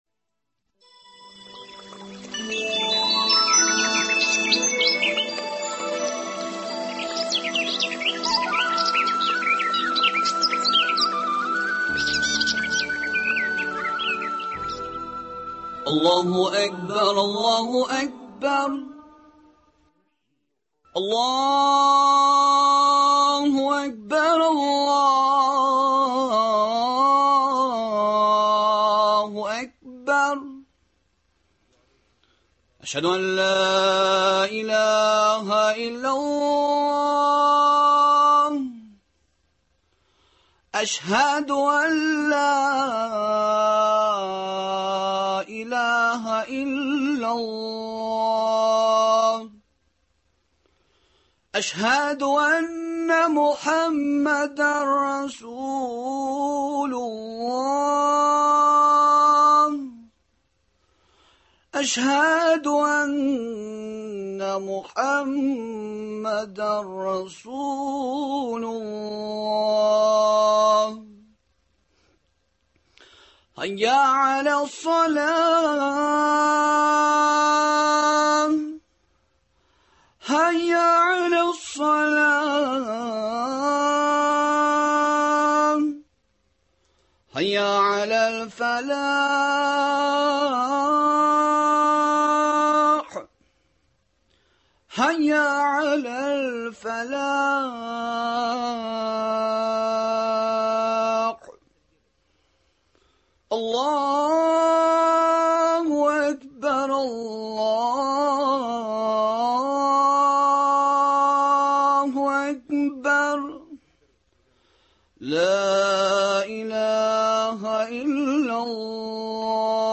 Студиябезнең кунагы